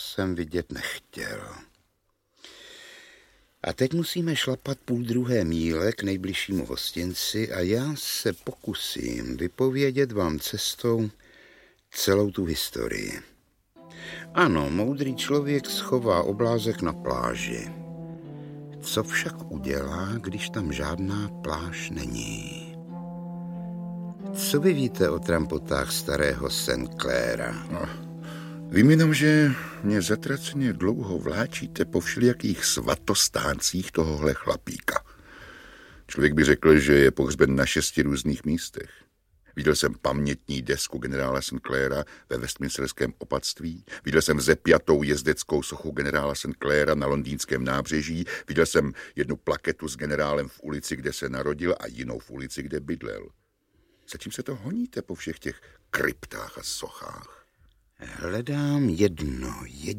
Audiobook
Read: Rudolf Hrušínský